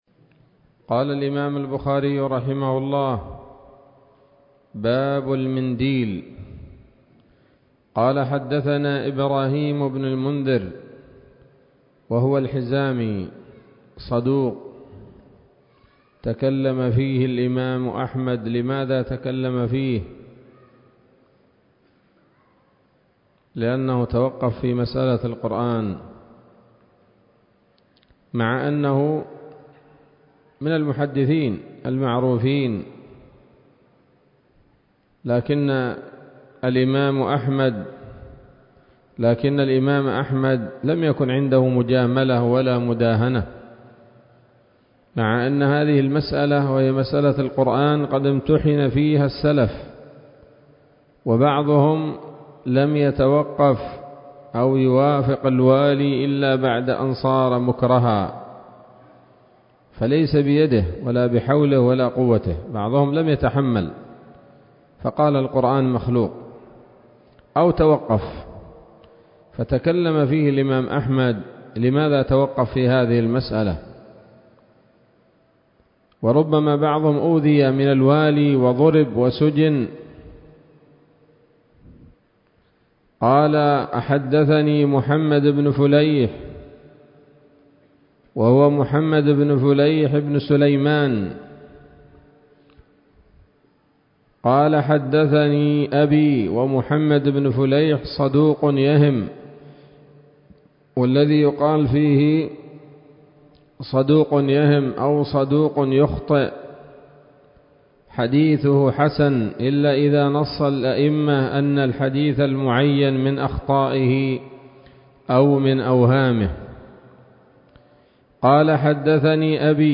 الدرس الثامن والعشرون من كتاب الأطعمة من صحيح الإمام البخاري